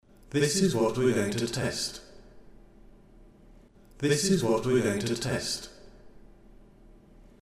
this. I recorded some speech, then applied both delay and reverb in
delayreverb.mp3